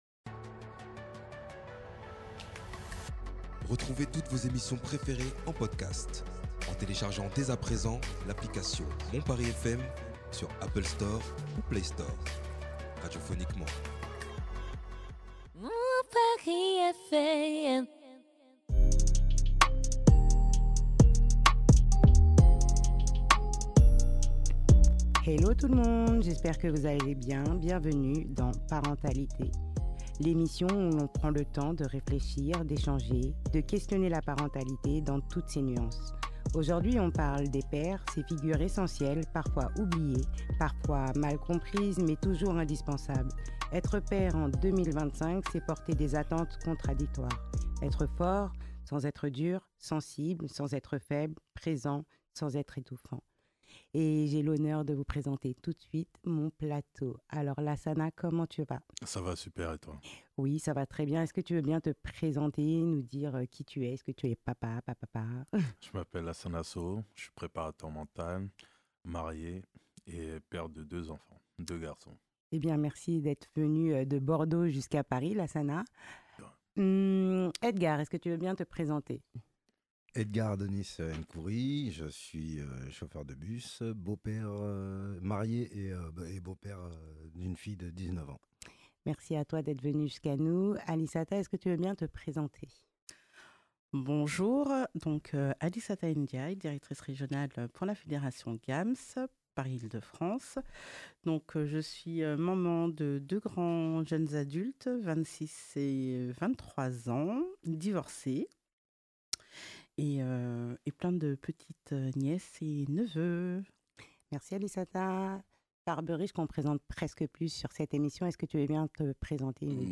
Ensemble, ils ont échangé autour d’un thème essentiel : la place des hommes et des pères dans la société d’aujourd’hui. Entre confidences, réflexions et partages d’expériences, cet épisode met en lumière la diversité des parcours et la richesse des regards sur la paternité, la coéducation et les défis du quotidien.